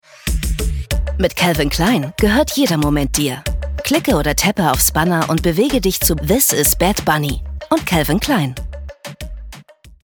markant, sehr variabel, hell, fein, zart
Jung (18-30)
Commercial (Werbung)